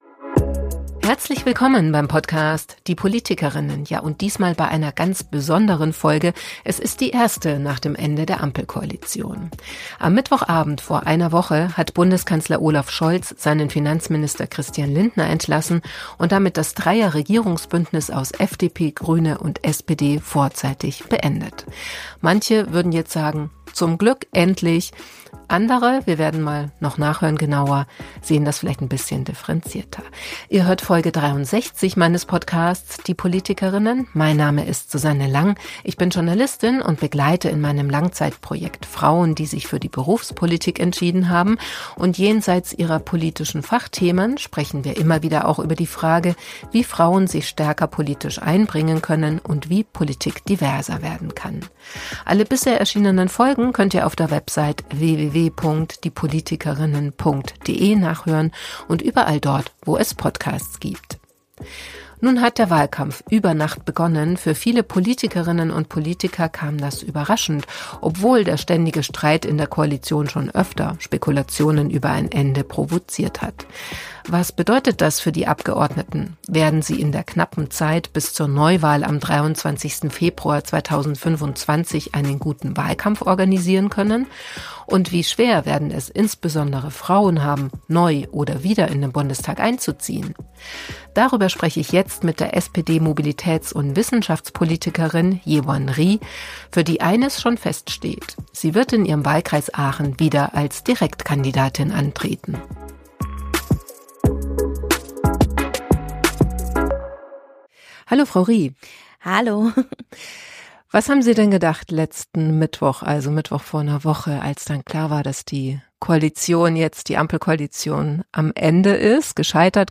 Wie hat sie das Ampel-Ende erlebt und was möchte sie in der verbleibenden Zeit noch erreichen? Darüber habe ich mit der SPD-Mobilitäts- und Wissenschaftspolitikerin in ihrem Büro im Otto-Wels-Haus gesprochen.